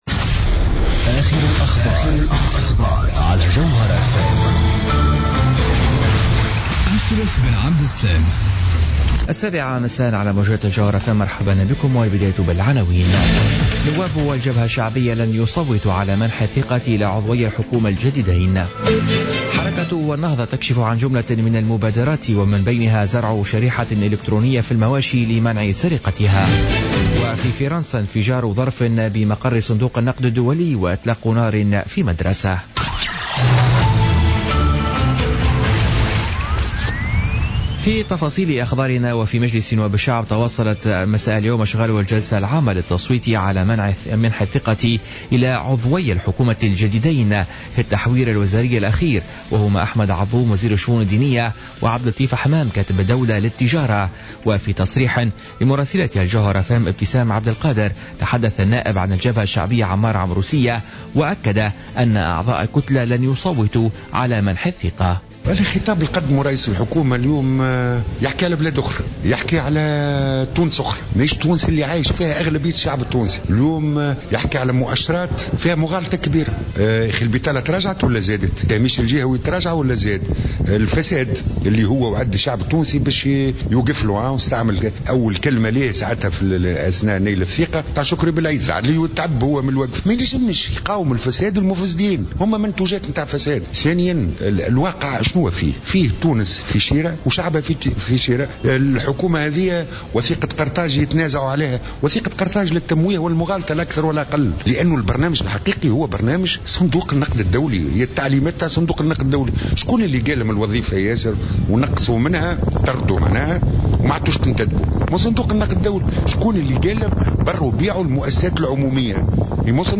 نشرة أخبار السابعة مساء ليوم الخميس 16 مارس 2017